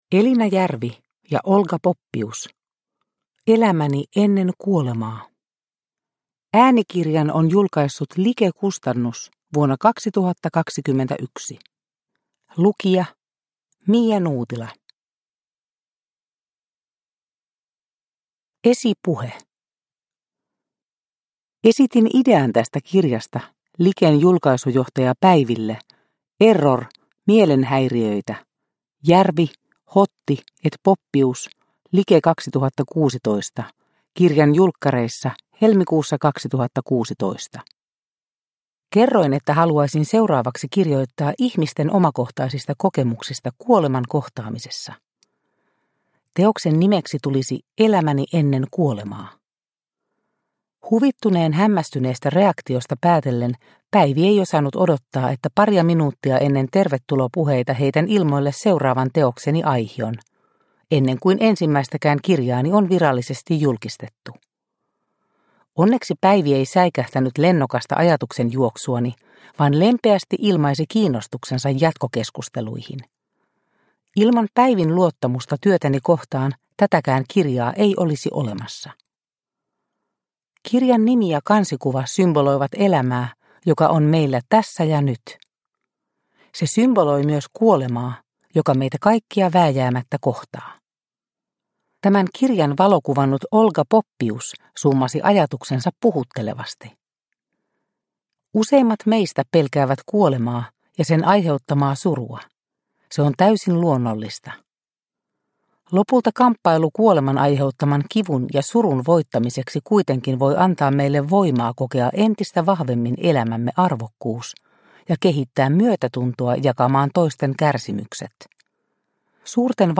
Elämäni ennen kuolemaa – Ljudbok – Laddas ner